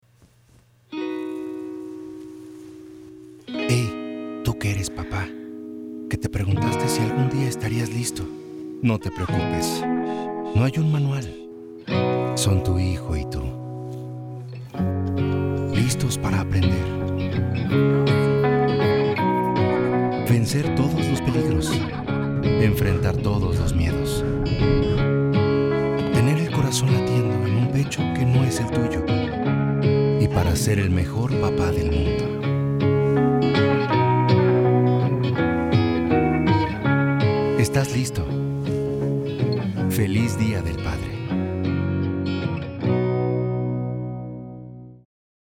Âge vocal : 25 à 65 ans
Styles : chaleureux, élégant, naturel, conversationnel, vendeur, corporatif, juridique, profond.
Équipement : Neumann TLM 103, Focusrite Scarlett, Aphex Channel, Source Connect